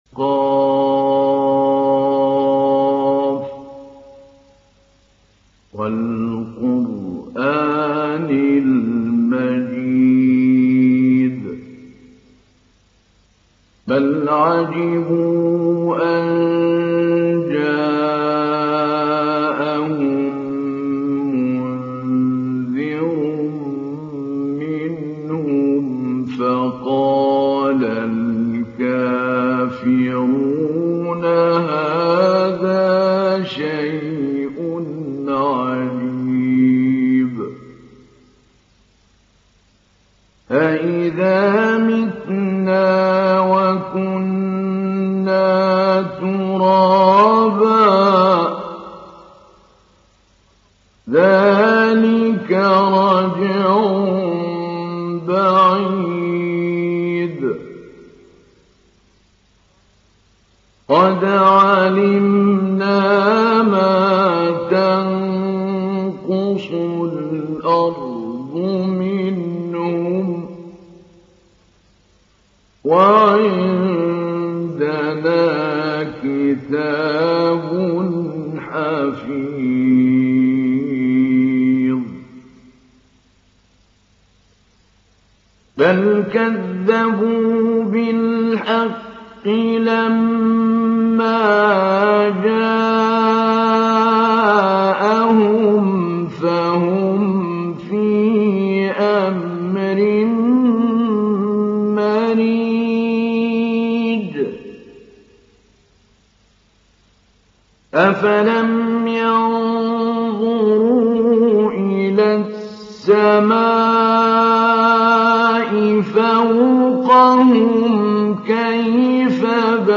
Surah Qaf Download mp3 Mahmoud Ali Albanna Mujawwad Riwayat Hafs from Asim, Download Quran and listen mp3 full direct links
Download Surah Qaf Mahmoud Ali Albanna Mujawwad